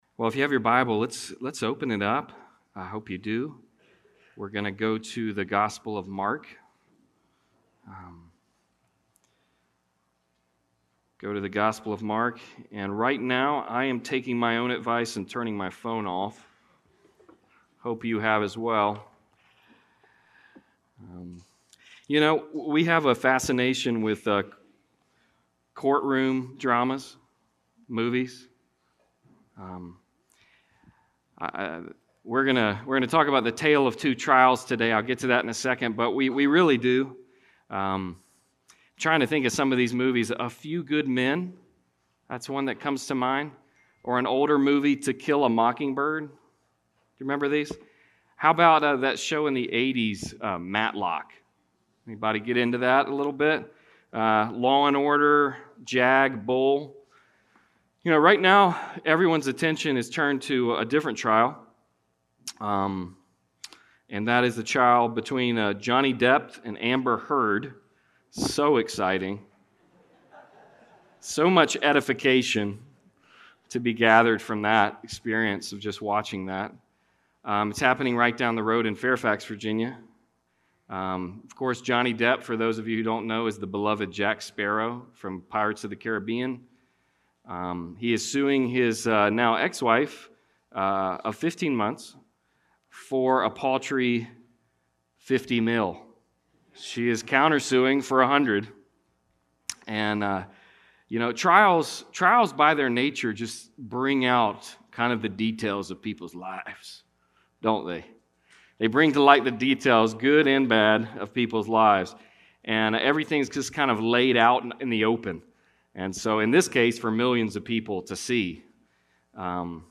Passage: Mark 14:53-72 Service Type: Sunday Service